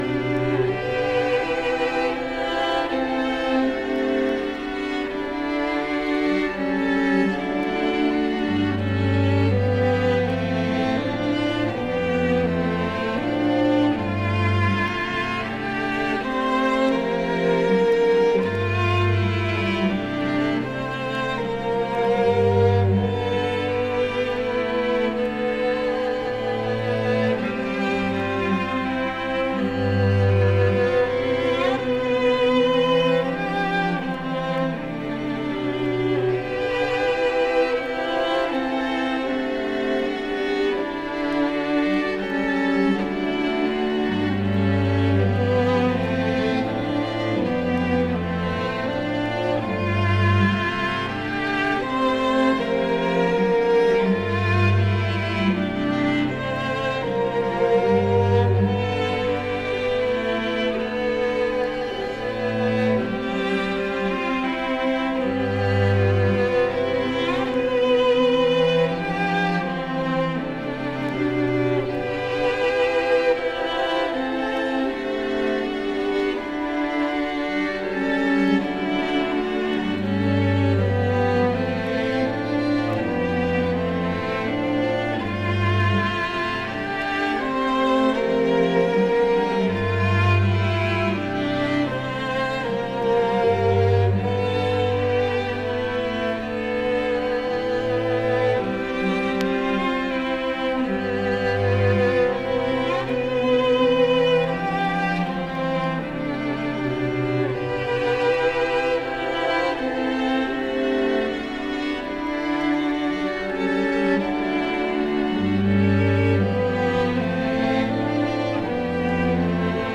Electronix